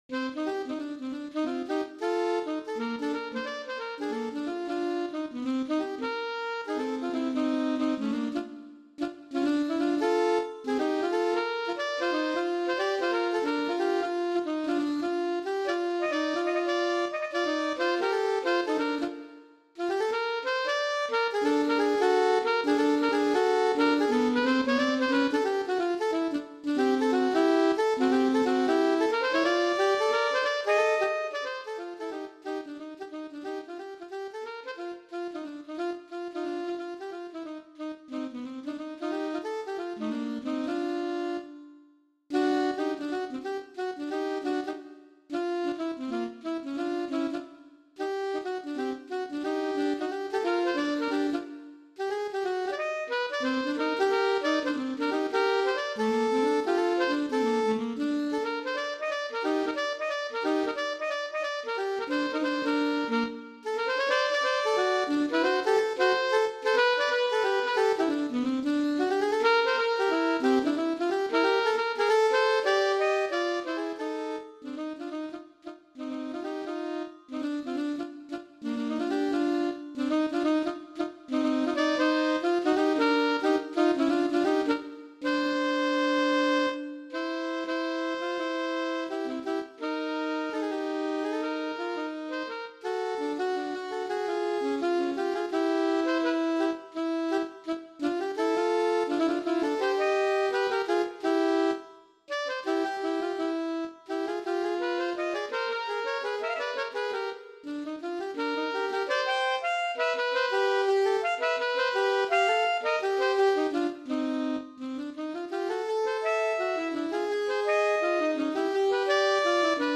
Gattung: Für 2 Altsaxophone